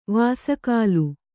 ♪ vāsa kālu